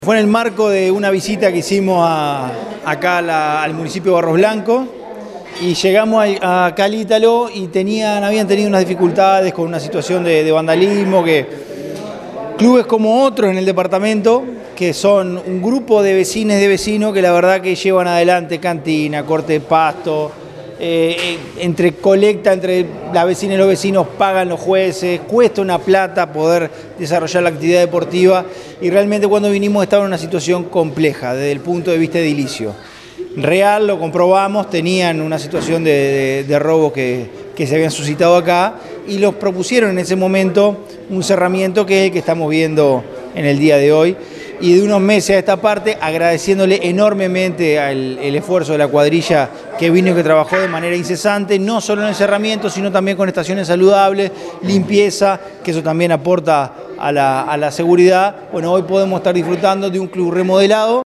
Pro Secretario General, Francisco Legnani, en el aniversario del Club Ítalo Americano de Barros Blancos
pro_secretario_general_francisco_legnani.mp3